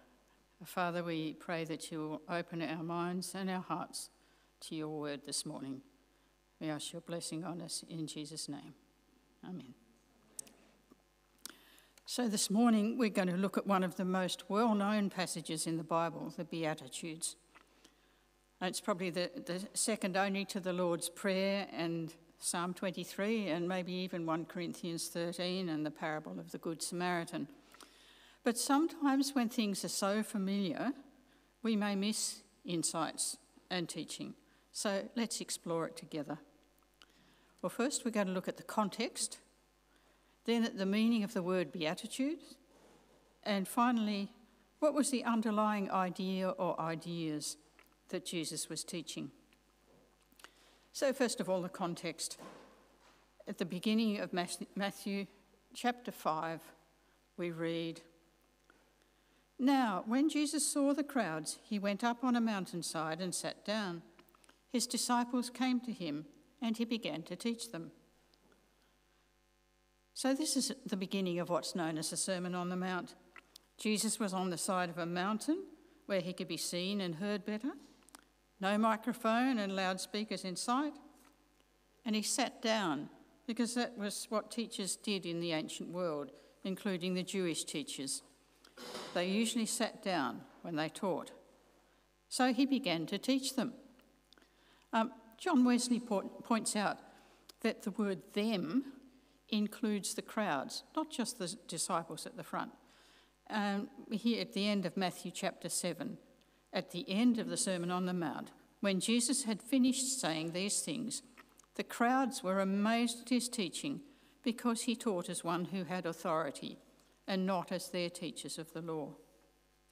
The Beatitudes Preacher